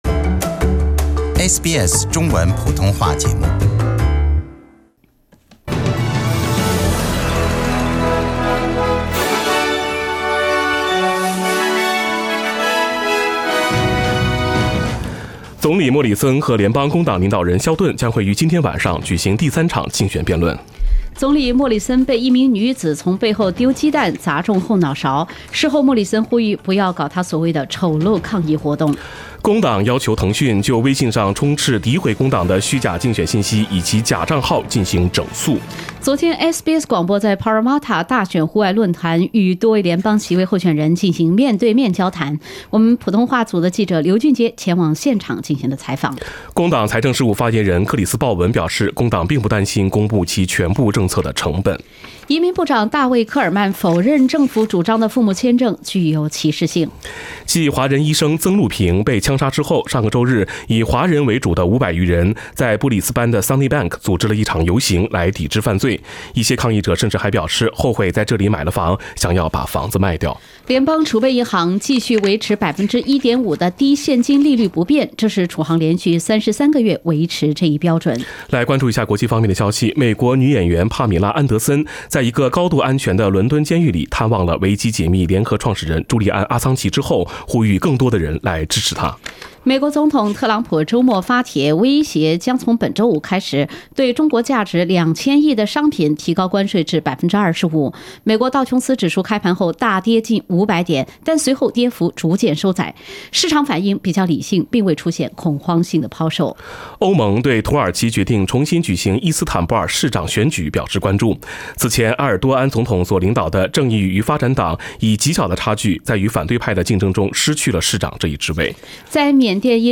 SBS早新闻（5月8日）